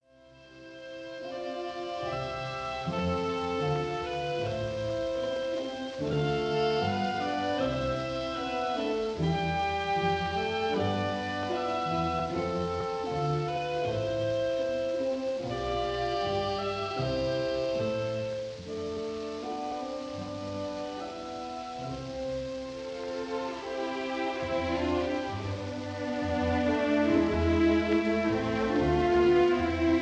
a brilliantly executed performance
swift and breezy
in very good sound
this performance has a freshness and sparkle.